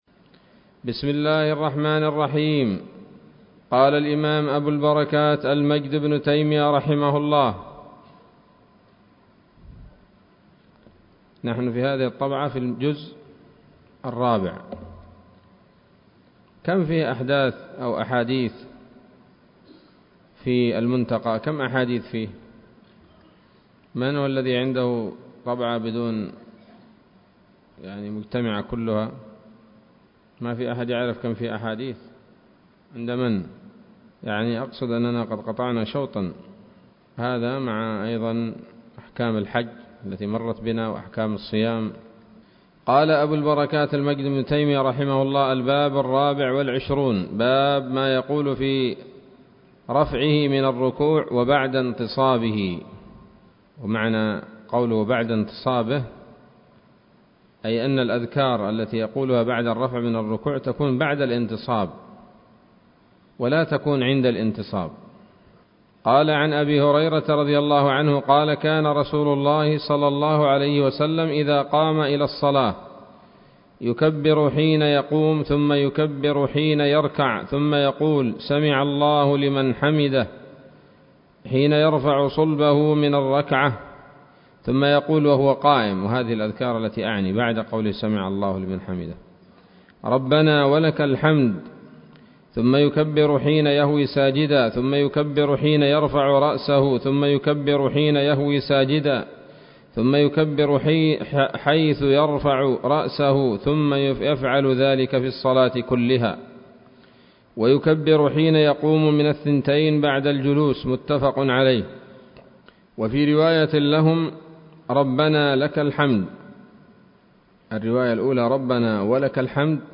الدرس الثامن والخمسون من أبواب صفة الصلاة من نيل الأوطار